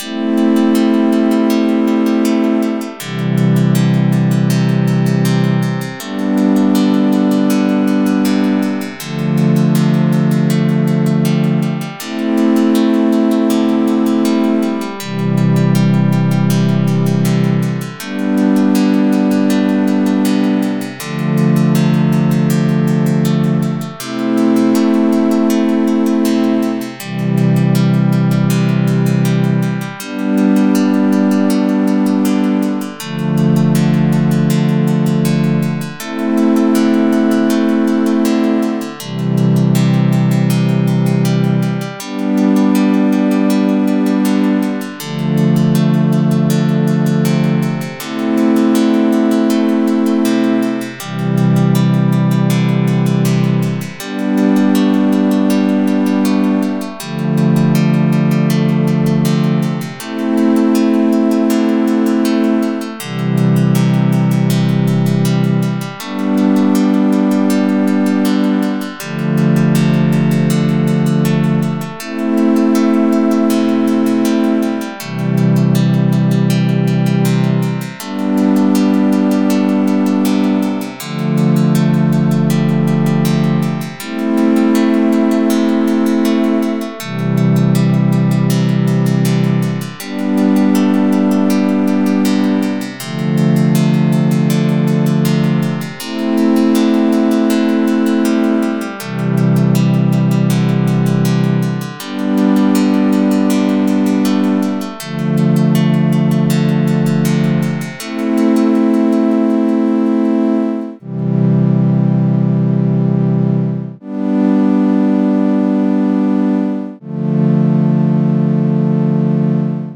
Very simple but it sounds good!
chords_and_melody.ogg